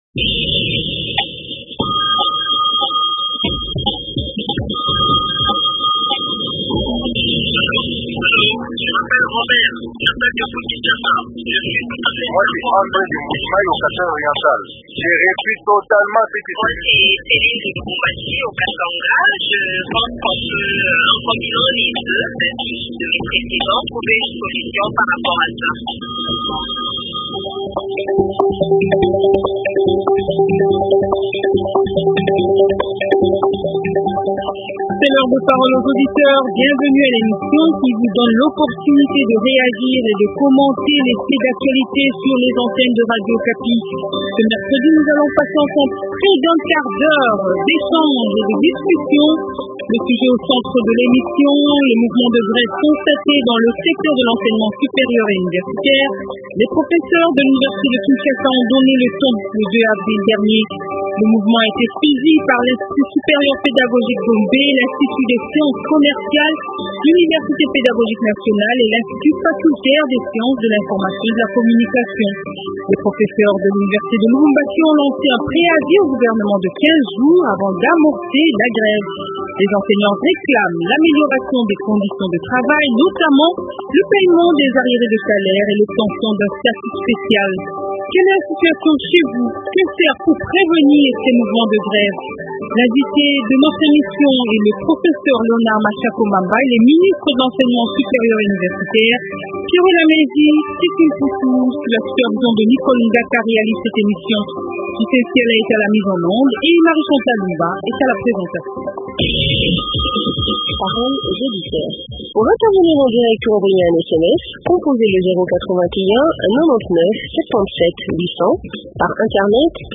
Invité : Professeur Leonard Mashako Mamba, ministre de l’enseignement supérieur et universitaire.